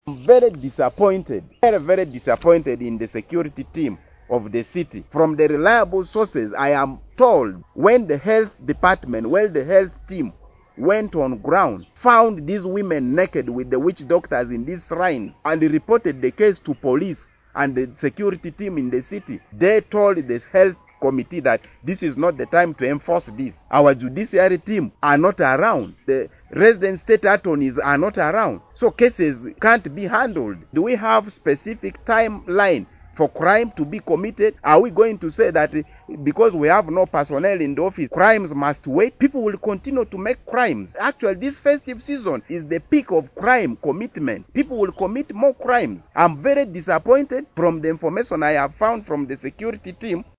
While addressing journalists at Hill top Hotel on Tuesday 28th/12/2022, the area Member of Parliament for Arua Central Division who doubles as the government chief whip, Hon. Jackson   Atimalee Butti expressed disappointment over the halting of the operation by the security officials, according to him the reason that his office received over the halting of the operations by the security officials were not evident enough to halt the operations.